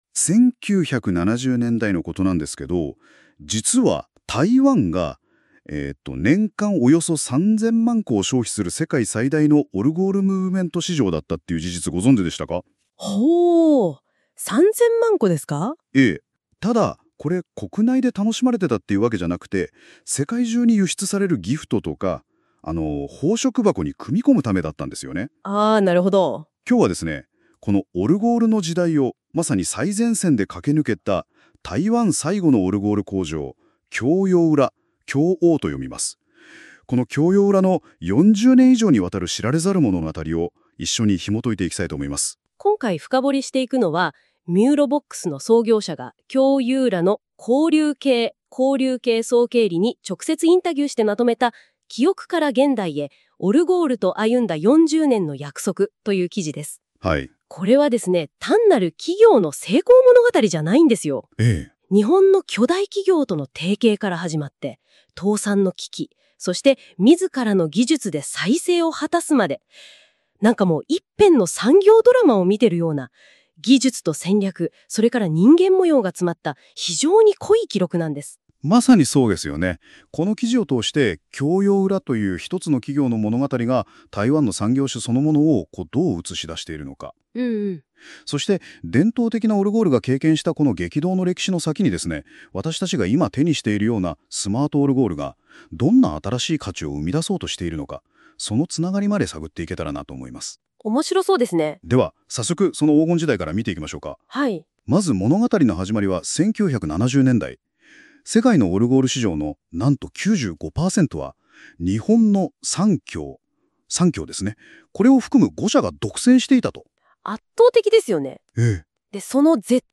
本ポッドキャスト音声はAIによって生成されたものであり、固有名詞の読み方や数値の詳細に誤りが含まれる可能性があります。